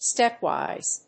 アクセント・音節stép・wìse